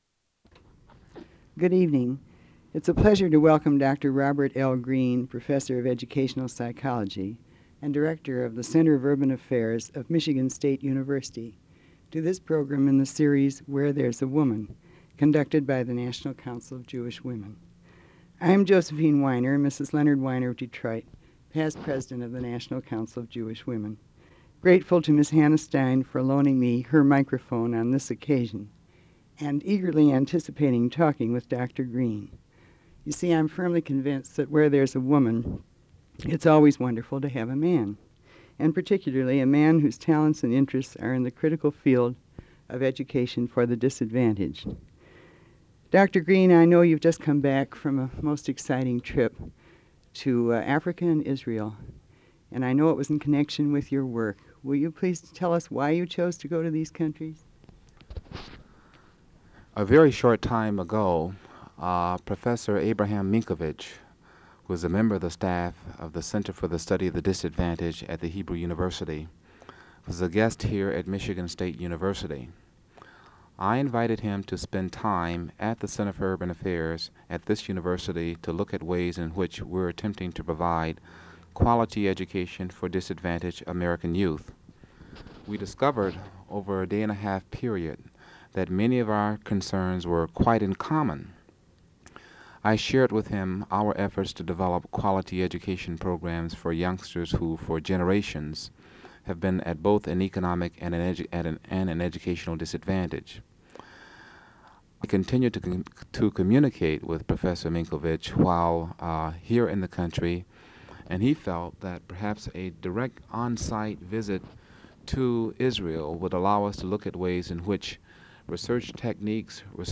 Interview
Original Format: Open reel audio tape